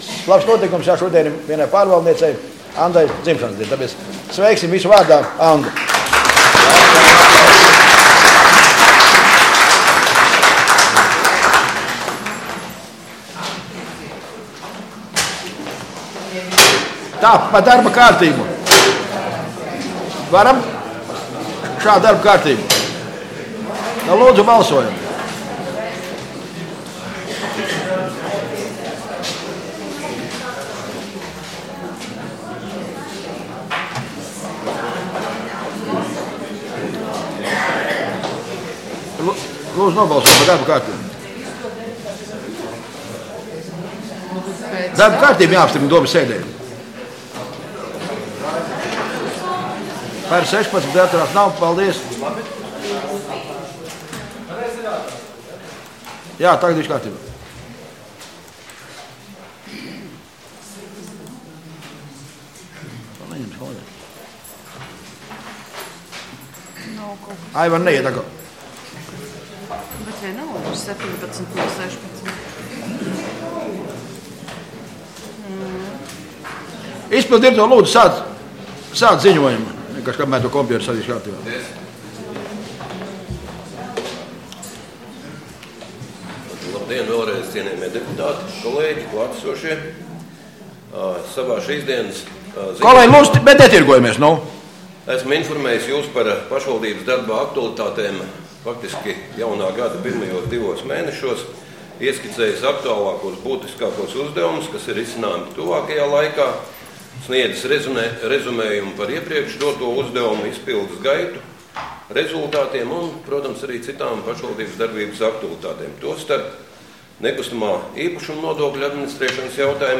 Domes sēde Nr. 3